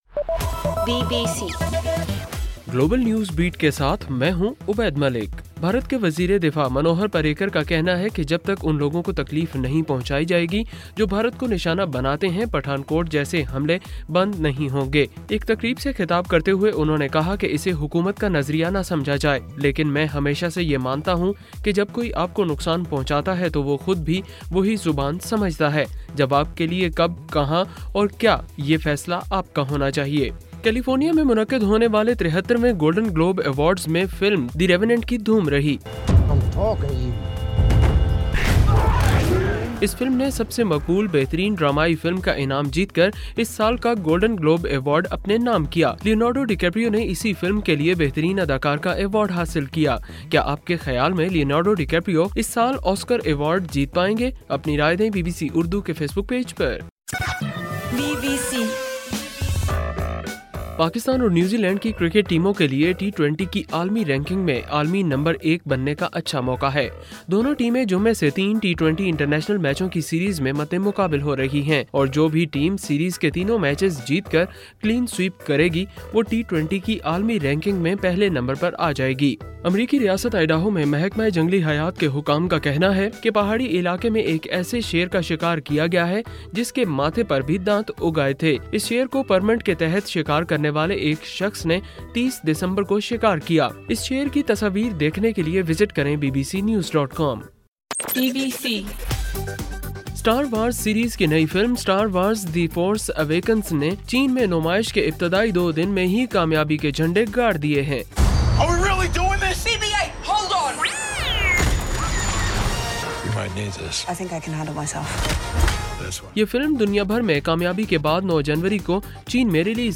جنوری 11: رات 11 بجے کا گلوبل نیوز بیٹ بُلیٹن